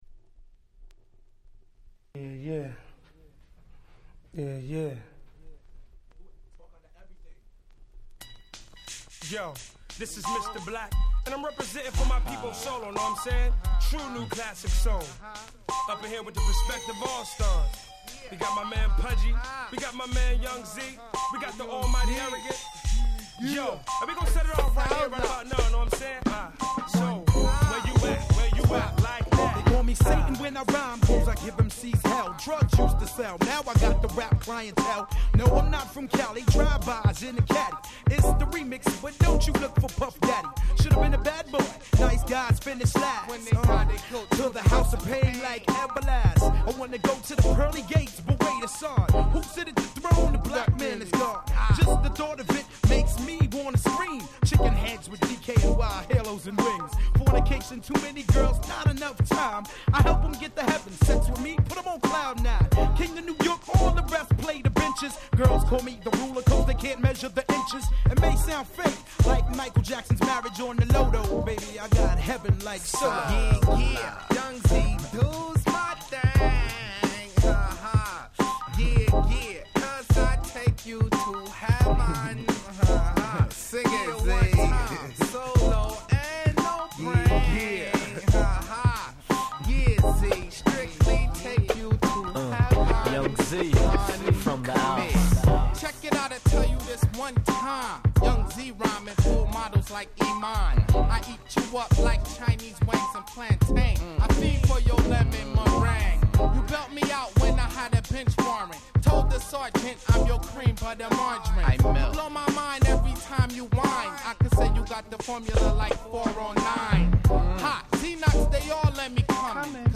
95' Nice Hip Hop Soul !!